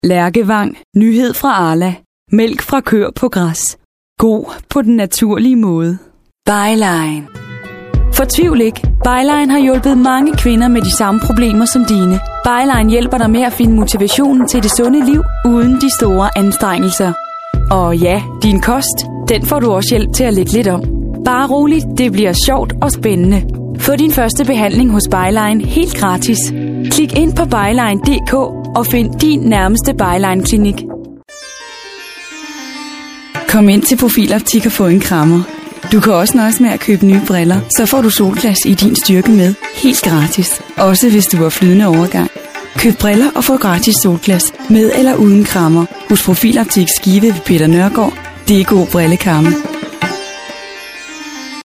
Sprecherin dänisch
Sprechprobe: Werbung (Muttersprache):
Professionell danish female voice over artist